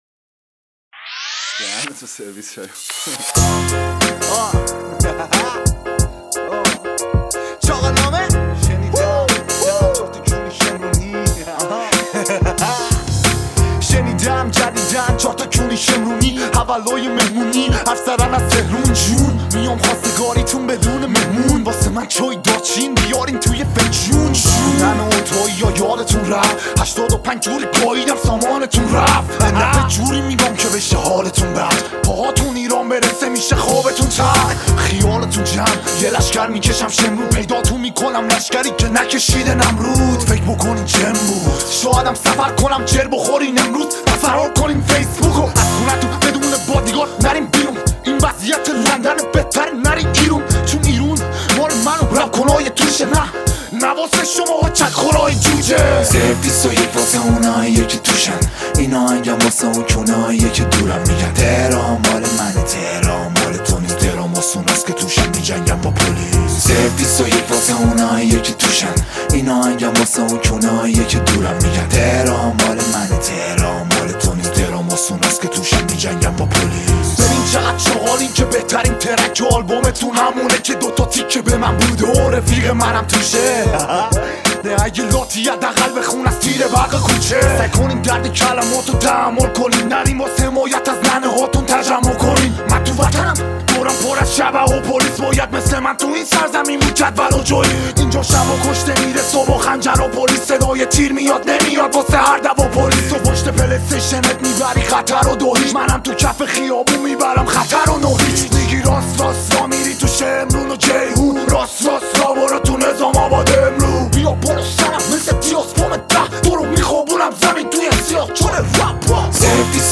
دیس بکی